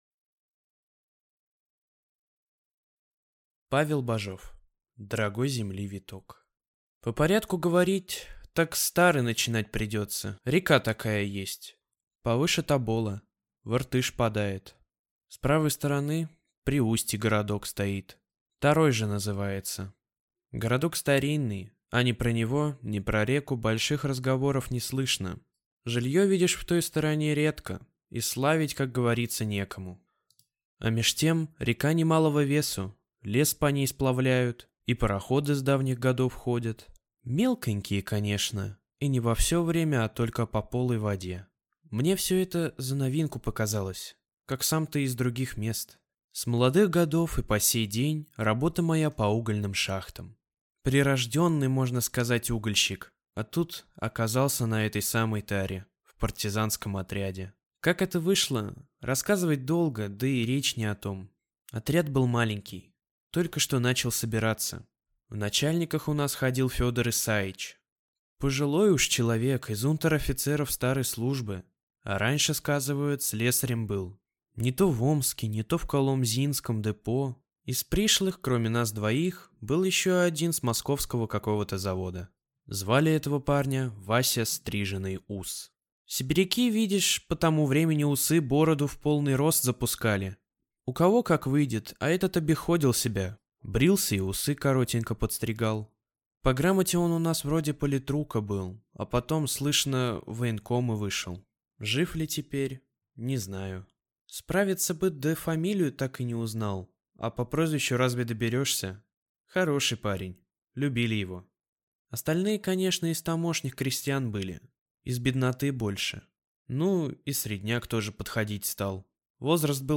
Аудиокнига Дорогой земли виток | Библиотека аудиокниг